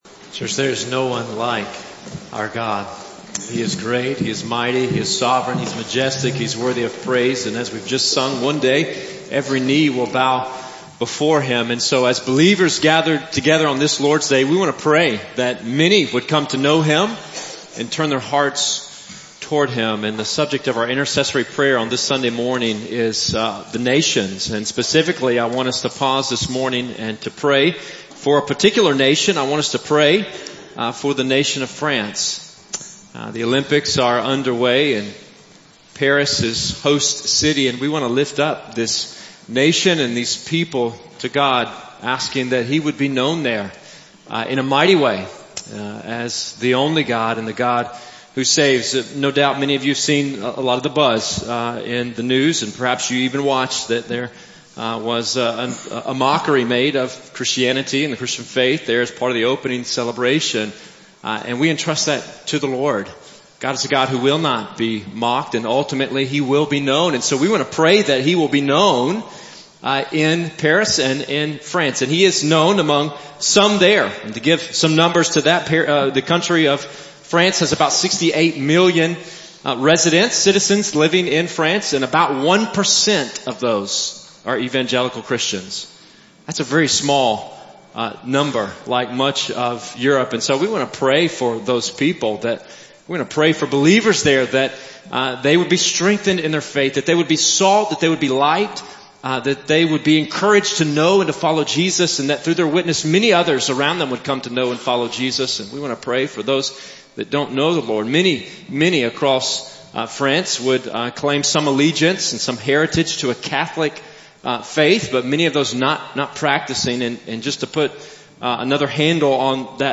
Meadow Brook Baptist Church, of Birmingham, Alabama, is happy to provide sermons from our Sunday morning services.